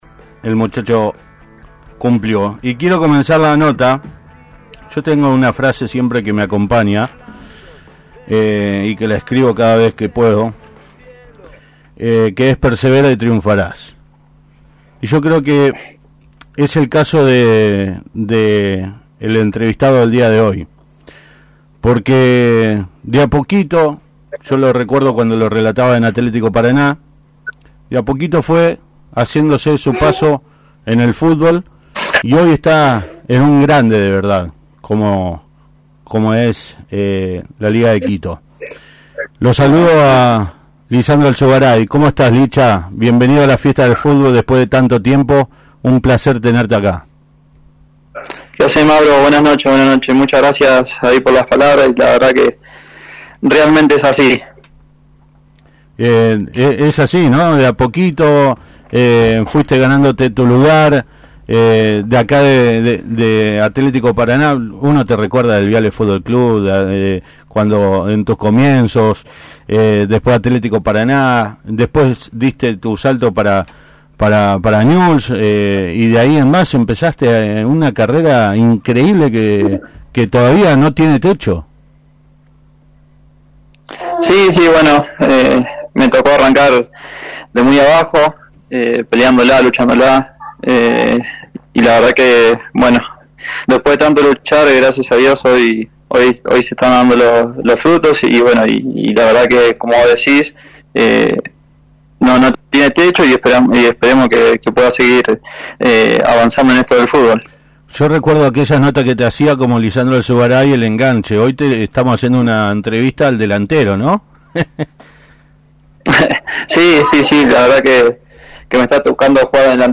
Desde Ecuador, el entrerriano charló con La Fiesta del Fútbol.